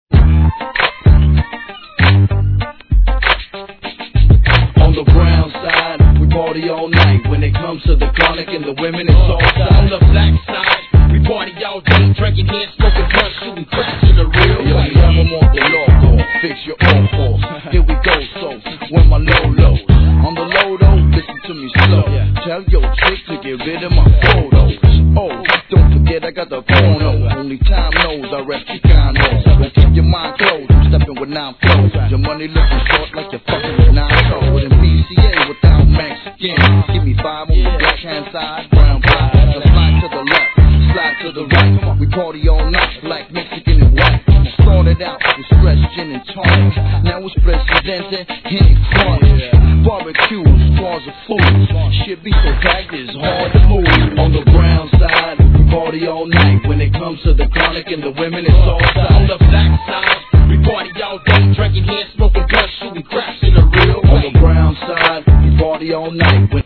G-RAP/WEST COAST/SOUTH
ファットなベースラインが心地よいミディアムFUNKです!